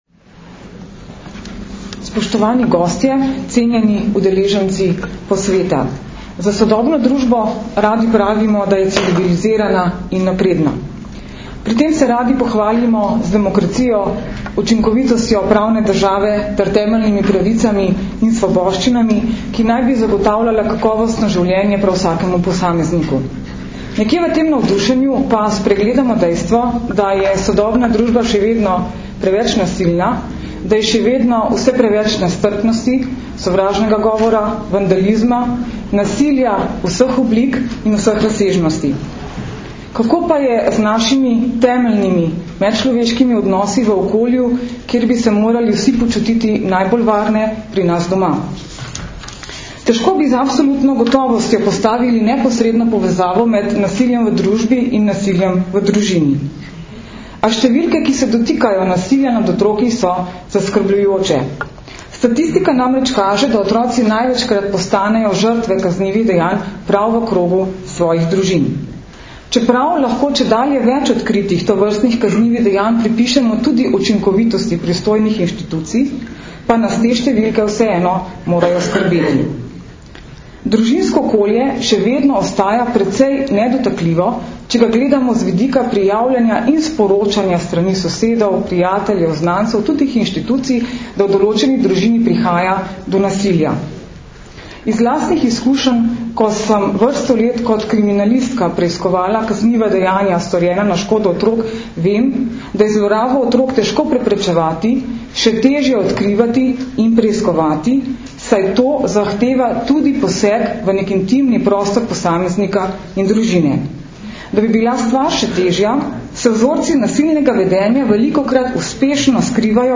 Na Brdu pri Kranju se je danes, 12. aprila 2012, začel dvodnevni strokovni posvet "Nasilje nad otroki – že razumemo?", ki ga letos že enajstič organizirata Generalna policijska uprava in Društvo državnih tožilcev Slovenije v sodelovanju s Centrom za izobraževanje v pravosodju.
Zvočni posnetek nagovora namestnice generalnega direktorja policije mag. Tatjane Bobnar (mp3)